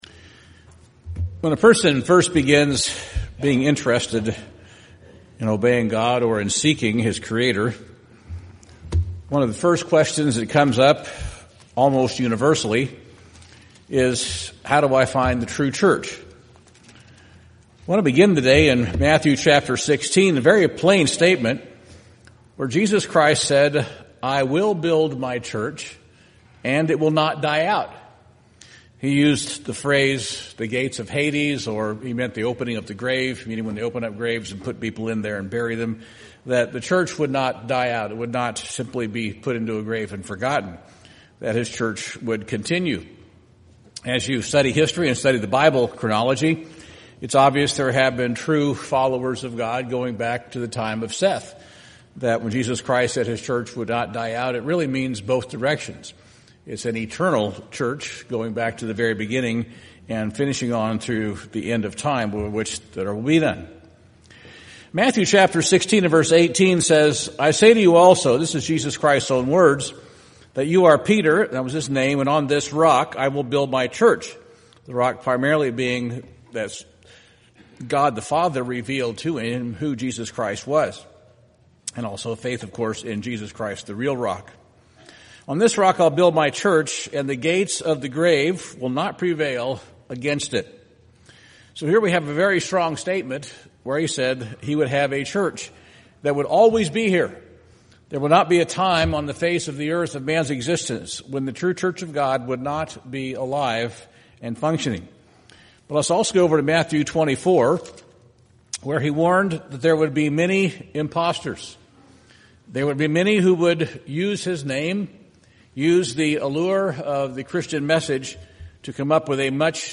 This sermon reveals the key markers of God's church.